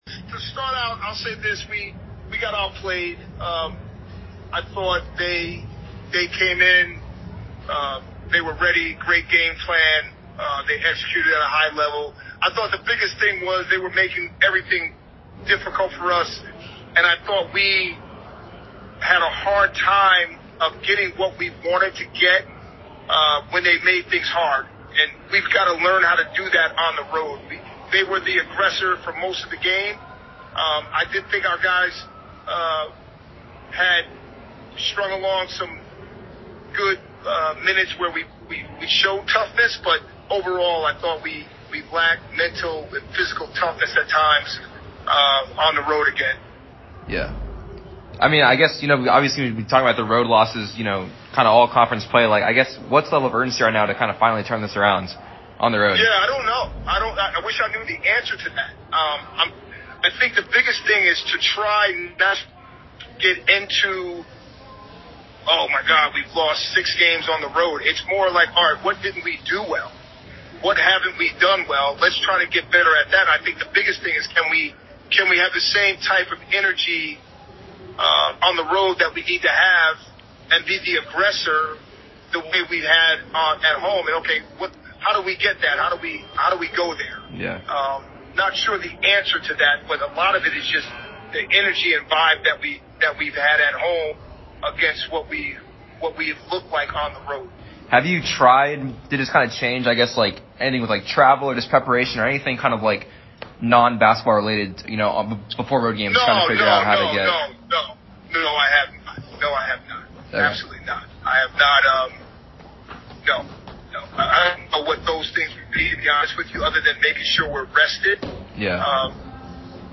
MBB_HC_Postgame.mp3